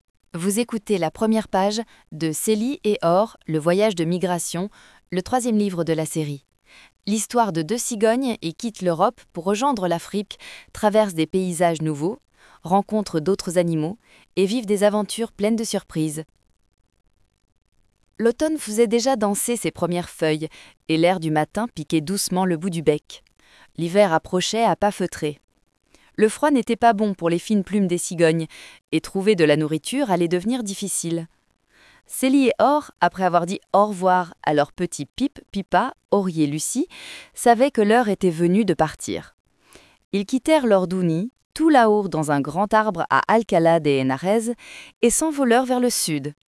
Lectures (audio)